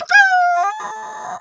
pgs/Assets/Audio/Animal_Impersonations/chicken_2_bwak_05.wav at master
chicken_2_bwak_05.wav